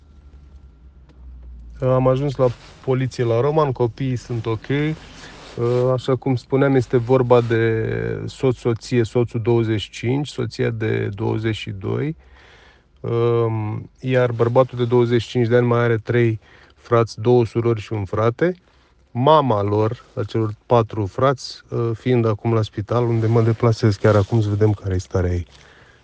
Actualizare. Prefectul Adrian Niță a revenit cu un mesaj anunțând că starea copiilor este bună.